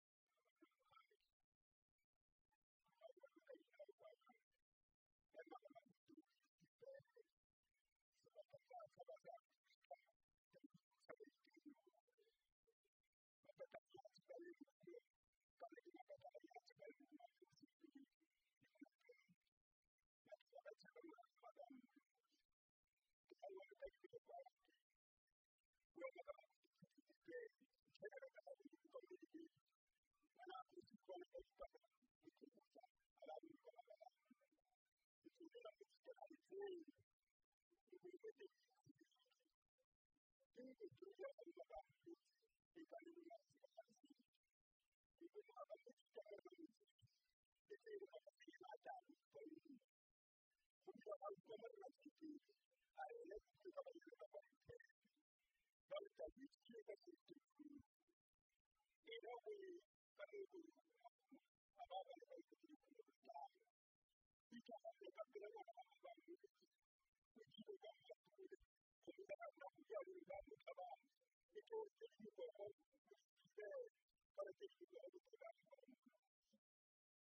Folk songs, Tswana
Folk music
Field recordings
sound recording-musical
Praise verse.
96000Hz 24Bit Stereo